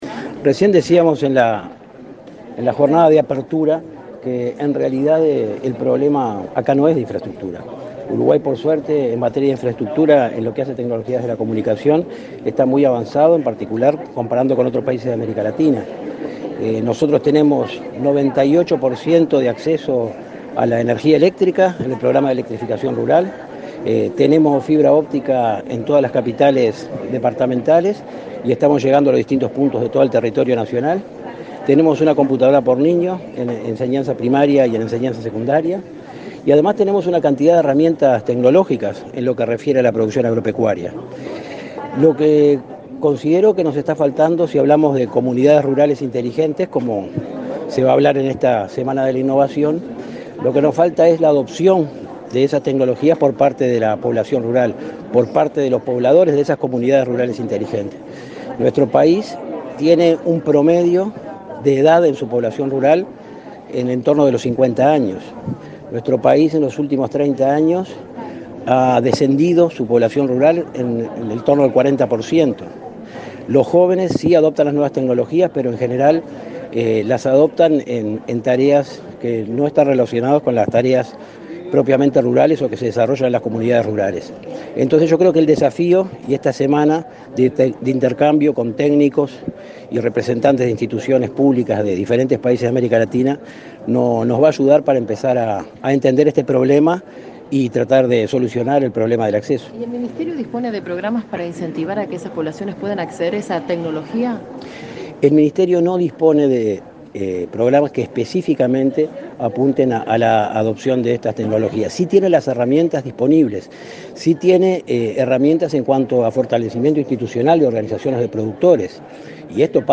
“Uruguay dispone de una amplia cobertura en conectividad y electrificación que permite a productores rurales acceder a tecnología y mejorar su productividad y comercialización”, afirmó el subsecretario de Ganadería, Alberto Castelar en la apertura de la Semana de la Innovación en América realizado este lunes en Montevideo. La infraestructura está, hace falta la adopción de la tecnología por parte de esta población, dijo.